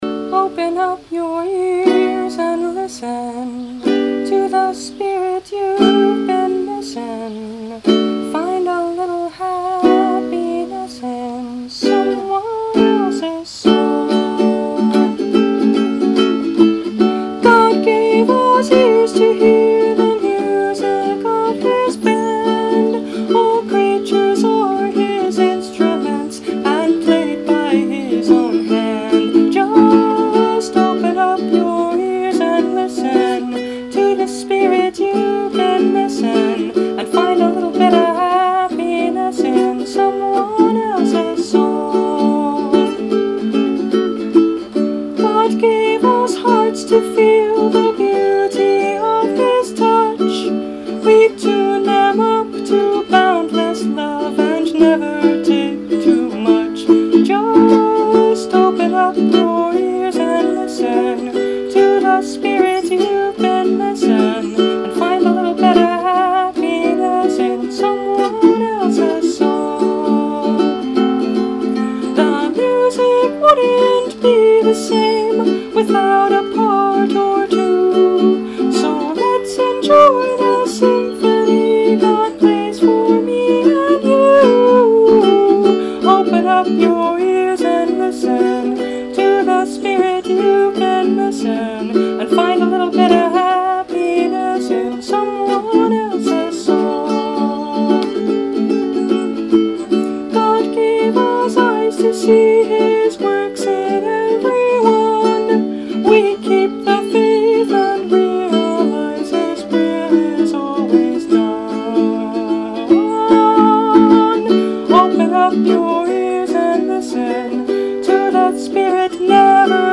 Instrument: Brio – Red Cedar Concert Ukulele
Recorded outside – listen for the birds!